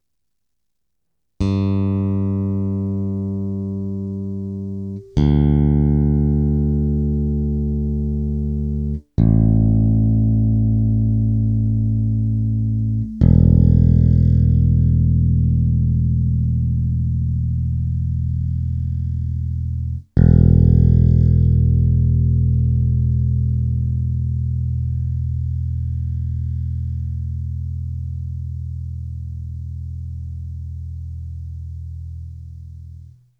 Re: drnčení strun
Mám problém teď na Warwicku, drnčí mi E struna a už fakt nevím co s tím. Všechny ostatní struny jsou OK, ale když zahraju prázdný éčko, tak to drnčí.